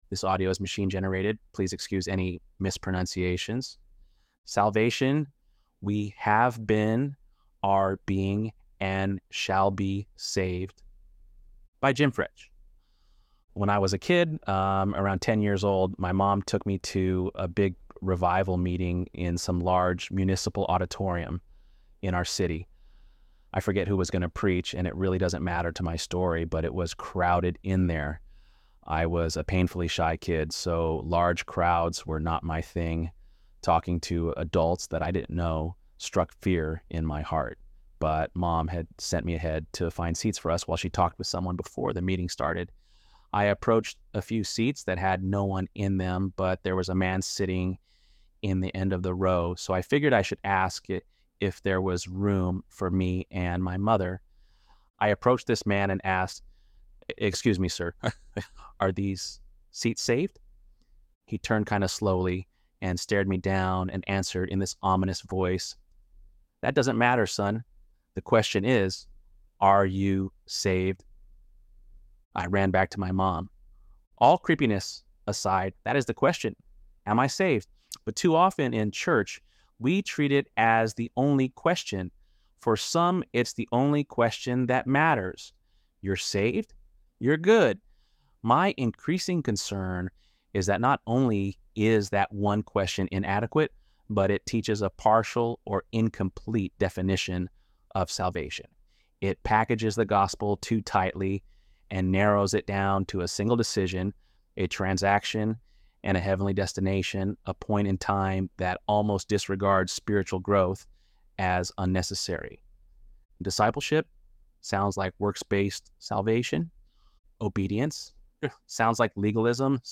ElevenLabs_6.21_Salvation.mp3